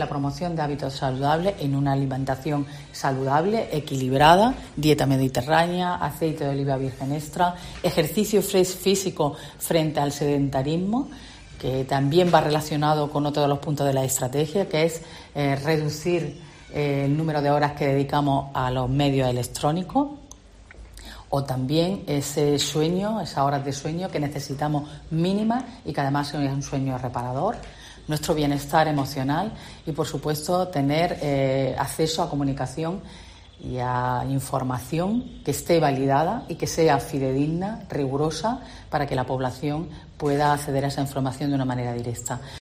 Catalina García, consejera de salud
La consejera ha señalado, a preguntas de los periodistas, que las críticas sobre una supuesta intención del Gobierno andaluz de privatizar la sanidad "caen por su propio peso", porque desde que el PP está en la Junta, ha dicho, la inversión en conciertos externos al Servicio Andaluz de Salud (SAS) ha bajado del 4,19 al 4,03 %.